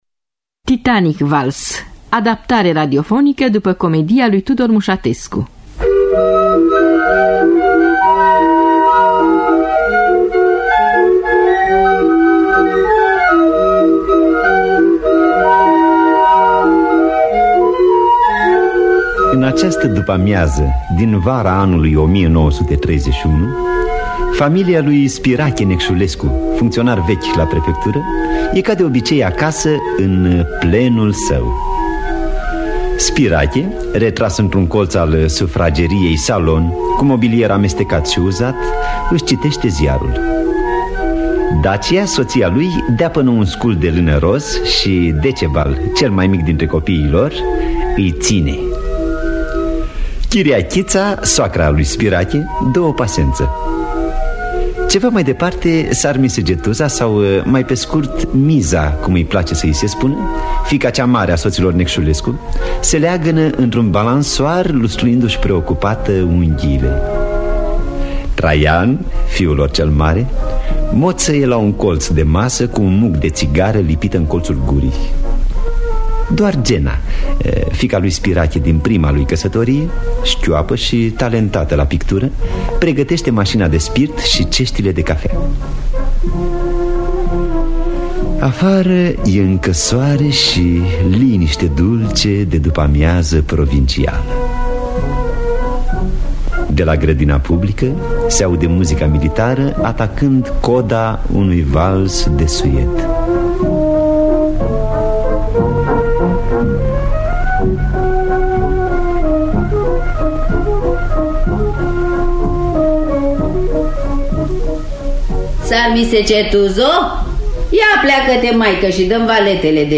Tudor Musatescu – Titanic Vals (1953) – Teatru Radiofonic Online